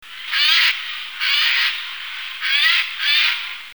Geai des chênes
geai_des_chenes_001.mp3